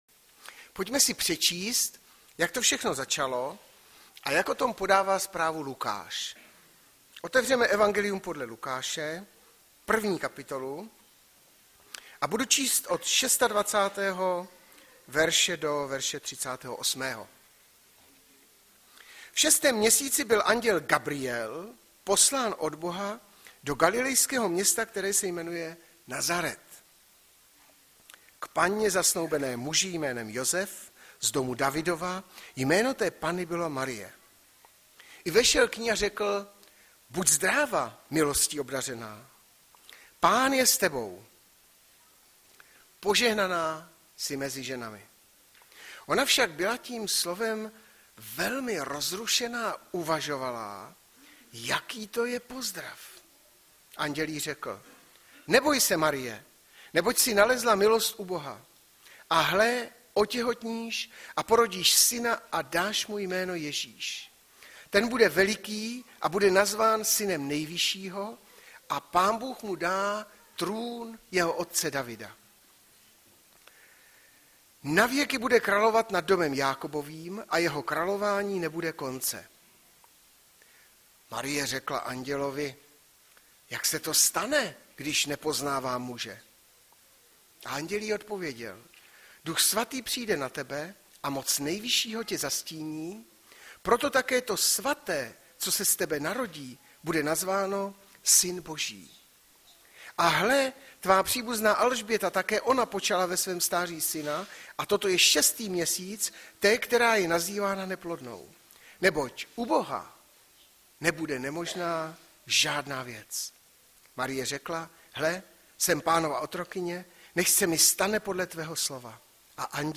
18.12.2016 - DĚTSKÁ VÁNOČNÍ SLAVNOST